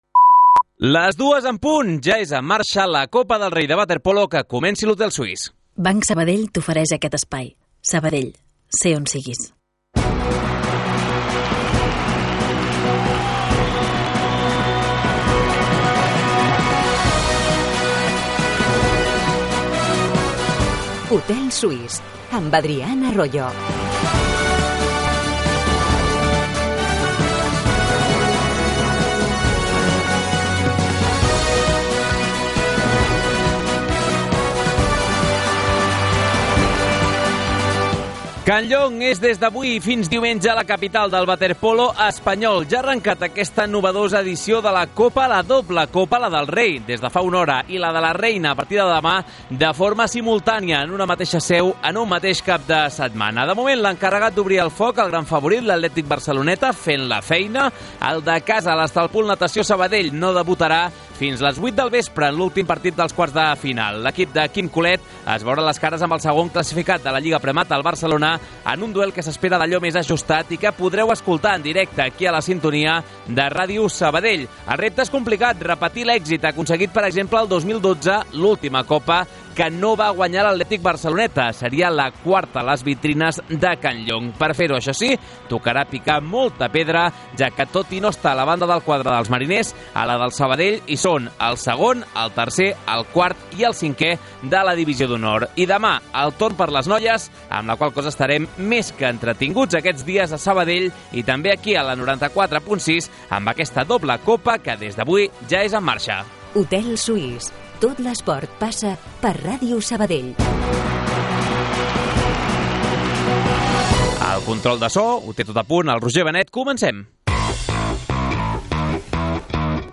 Les tertúlies esportives del recordat Hotel Suís de Sabadell prenen forma de programa de ràdio. Com passava llavors, l'hotel es converteix en l'espai reservat per a la reflexió, el debat i la conversa al voltant de l'esport de la ciutat.